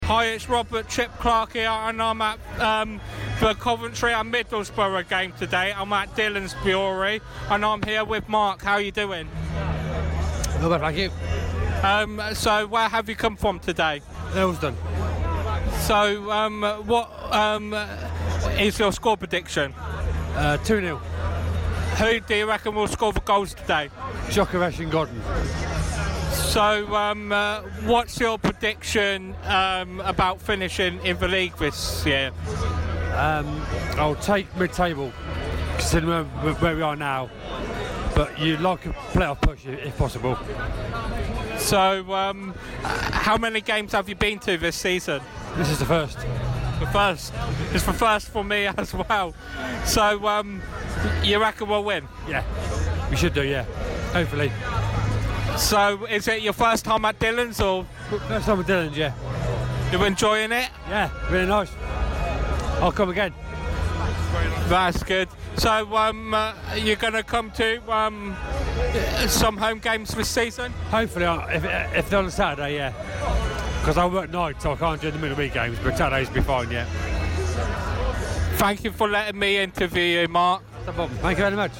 Interview
at Dhillons Brewery